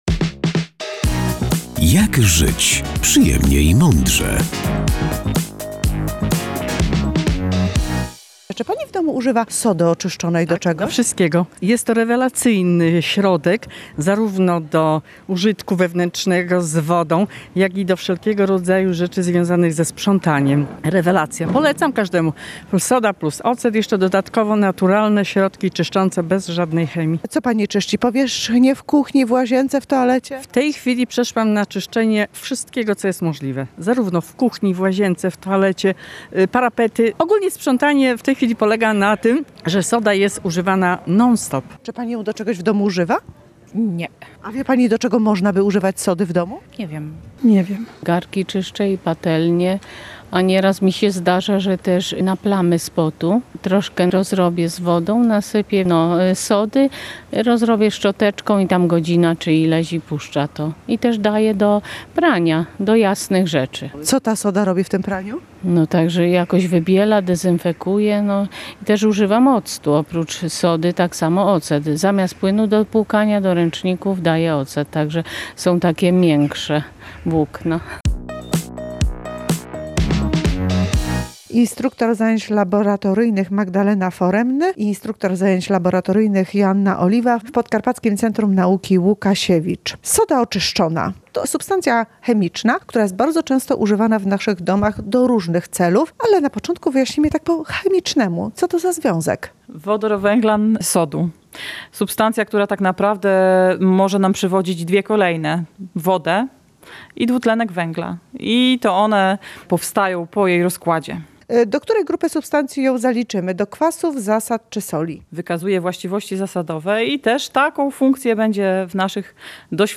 O jej wyjątkowych właściwościach opowiedziały instruktorki zajęć laboratoryjnych